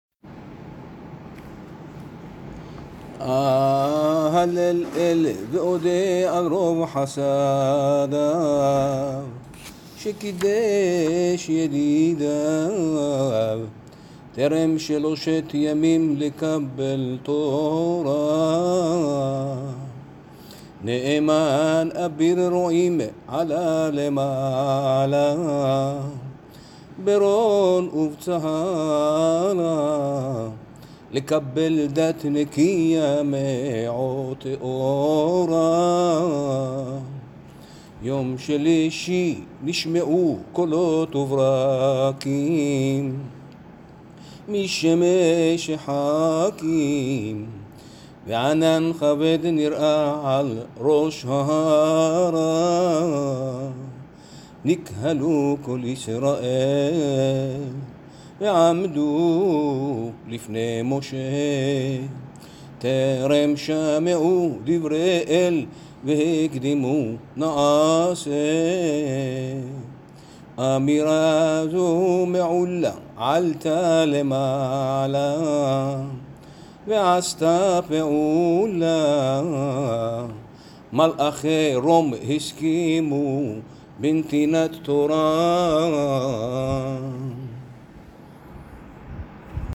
תיעוד פיוטי קהילת צפרו לחג השבועות – קהילת צפרו / סְפְרוֹ